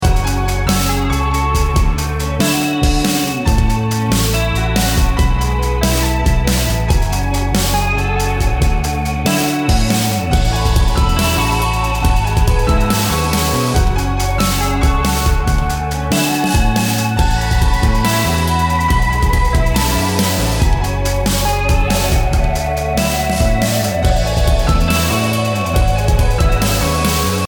528hz BPM140-149 Game Instrument Soundtrack インストルメント
BPM 140